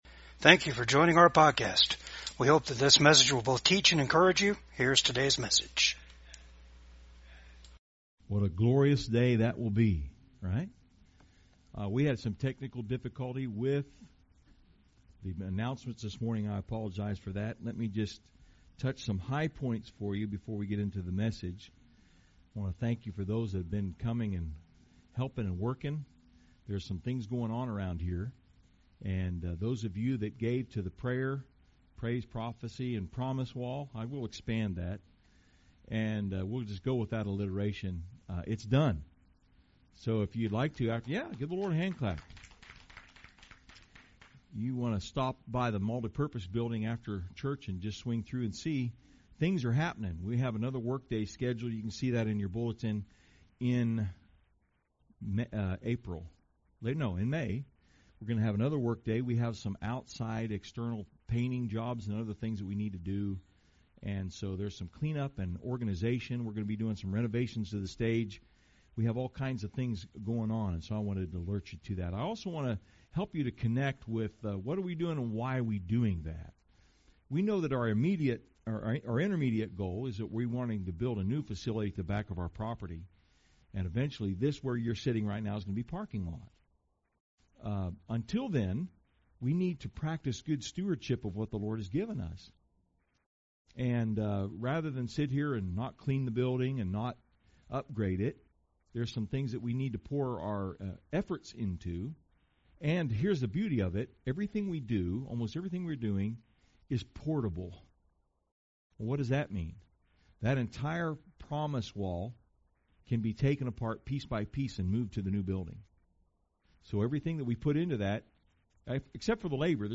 Passage: Luke 24:13-35 Service Type: VCAG SUNDAY SERVICE